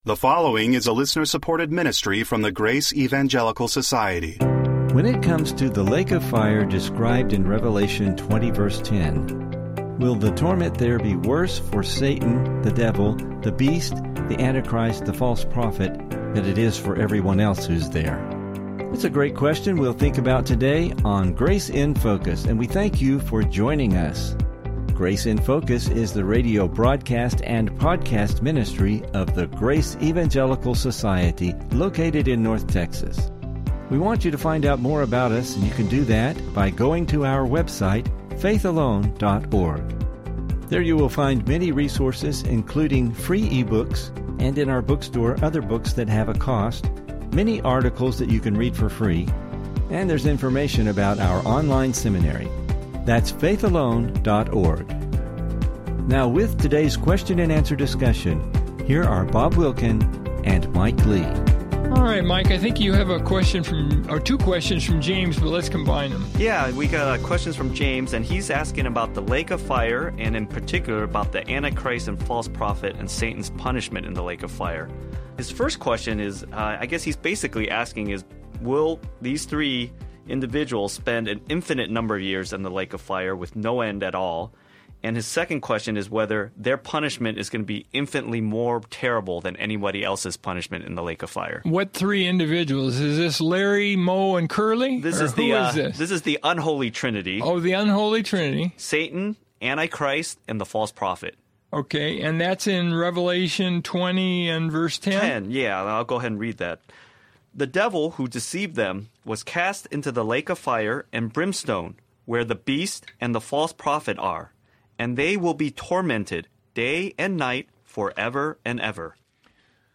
Welcome to the Grace in Focus radio.